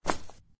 knock.mp3